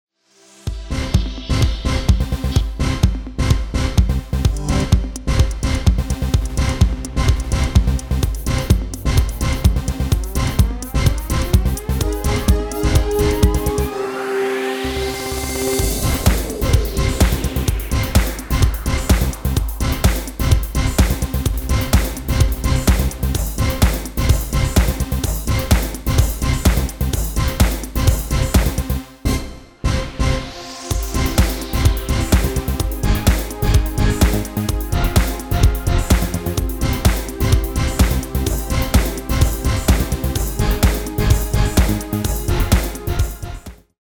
Genre: Dance / Techno / HipHop / Jump
Toonsoort: C
- Vocal harmony tracks
Demo = Demo midifile